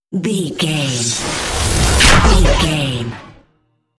Whoosh to hit technology
Sound Effects
dark
futuristic
intense
tension
the trailer effect